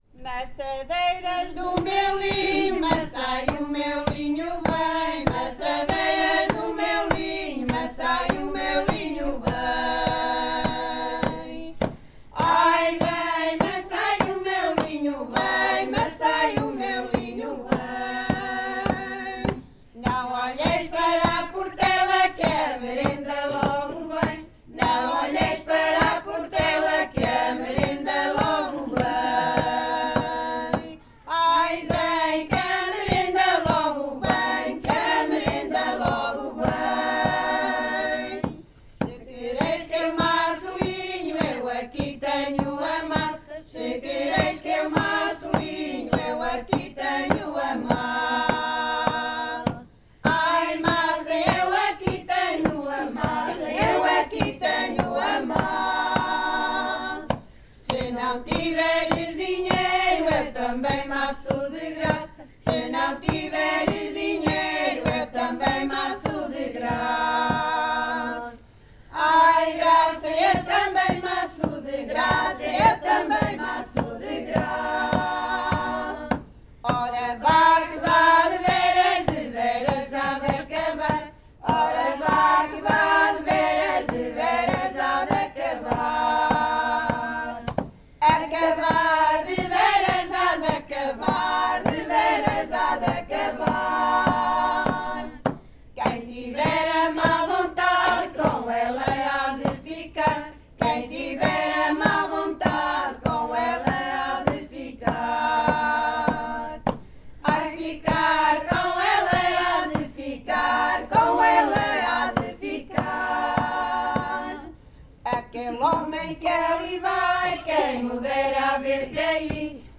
Grupo Etnográfico de Trajes e Cantares do Linho de Várzea de Calde durante o encontro SoCCos em Portugal - Maçadeiras do meu linho.